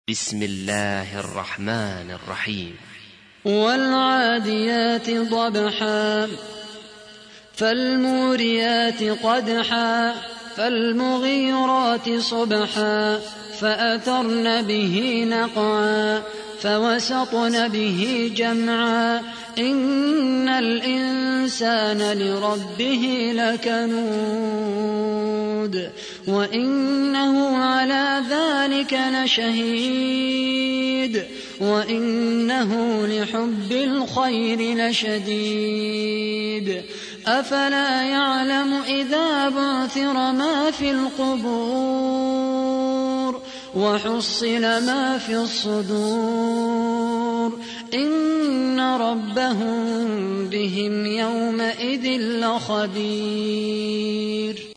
تحميل : 100. سورة العاديات / القارئ خالد القحطاني / القرآن الكريم / موقع يا حسين